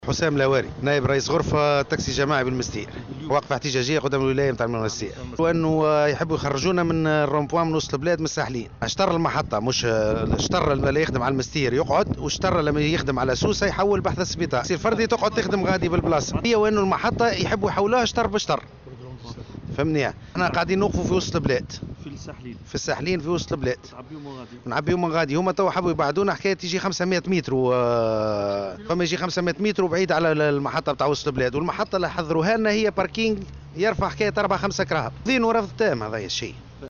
من جانبها، اعتبرت رئيسة المجلس البلدي بالساحلين، اسماء الصغير في تصريح لمراسل "الجوهرة أف أم" أنه تم إحداث محطة خاصة بخط الساحلين سوسة المنستير بالاشتراك مع المجتمع المدني، مشيرة إلى أن مكان وقوفهم القديم كان عشوائيا ويشكل خطورة على المارة.